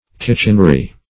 Search Result for " kitchenry" : The Collaborative International Dictionary of English v.0.48: Kitchenry \Kitch"en*ry\ (k[i^]ch"[e^]n*r[y^]), n. The body of servants employed in the kitchen; the staff of a kitchen.
kitchenry.mp3